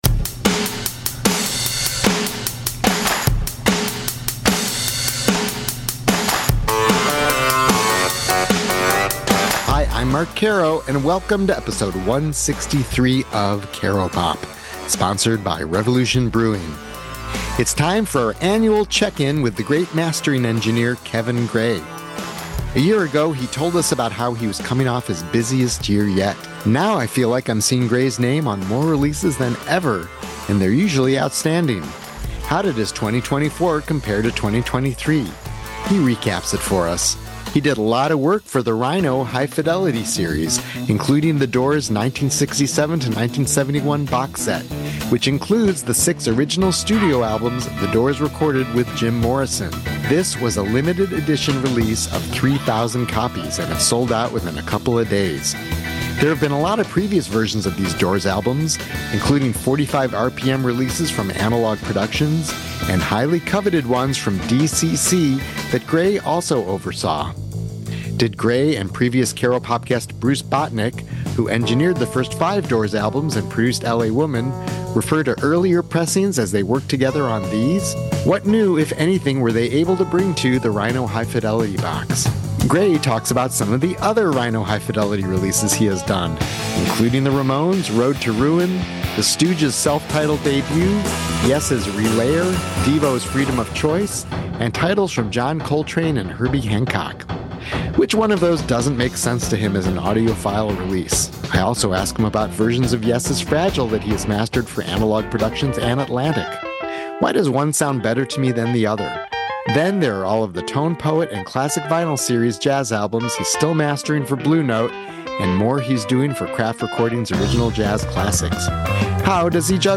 There may be nothing more inspiring and entertaining than relaxed, candid conversations among creative people.